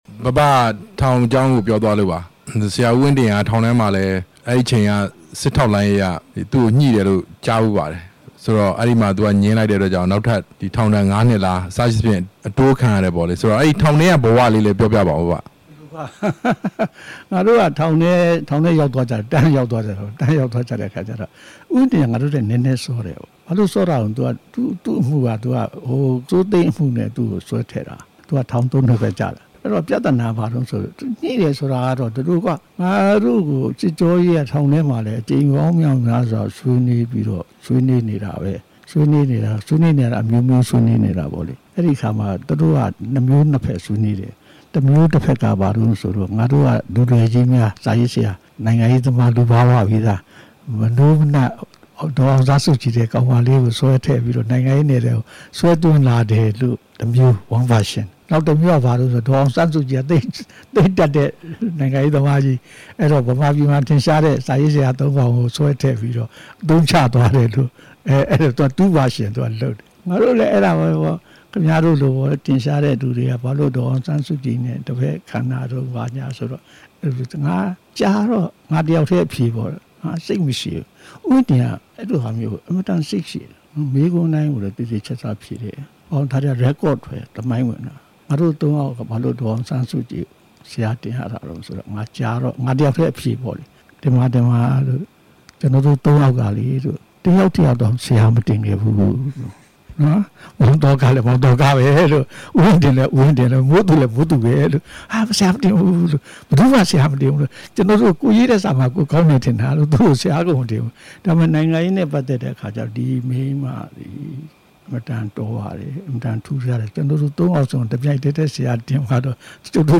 မေးမြန်းချက်(၂)